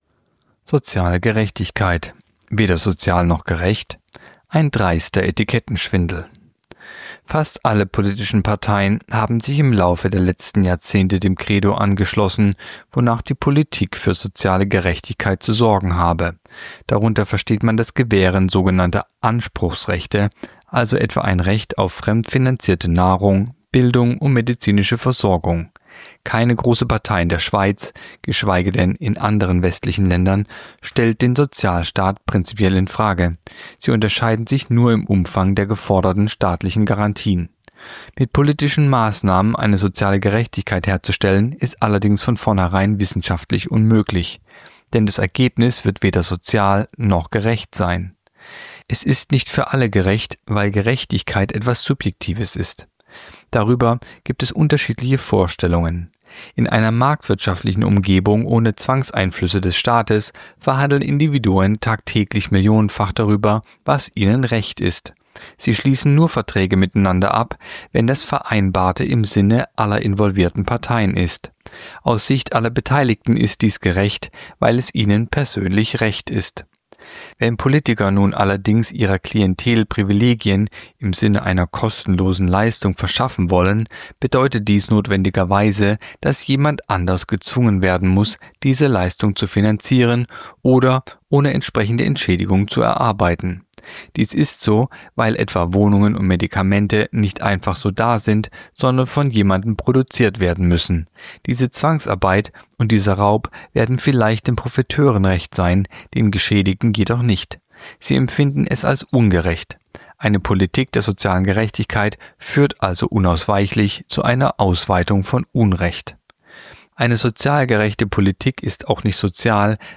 (Sprecher)